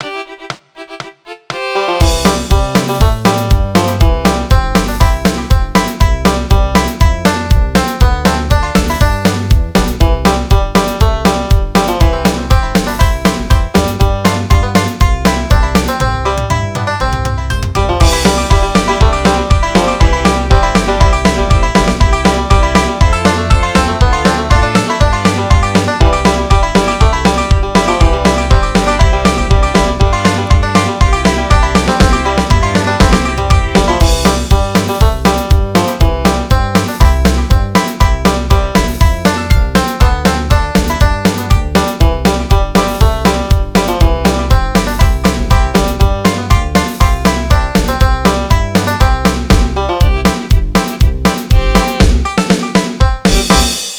Midi File, Lyrics and Information to Yellow Rose of Texas